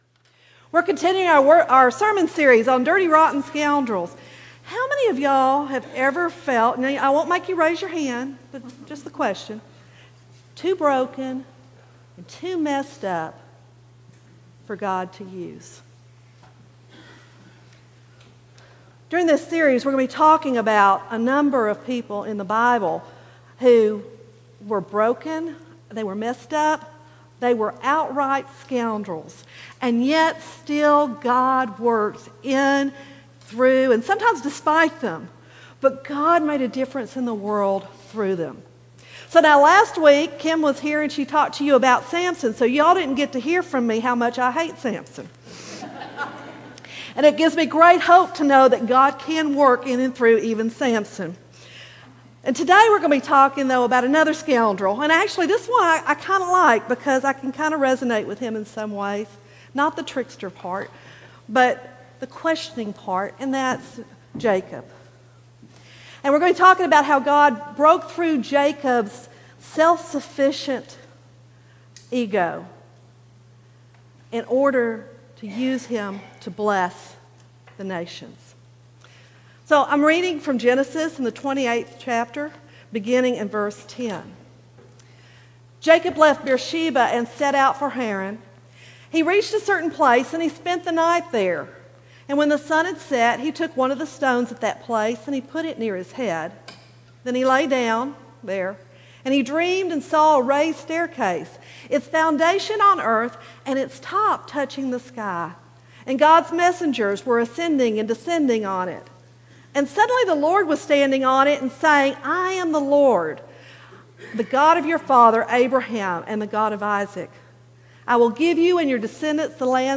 Aldersgate United Methodist Church Sermons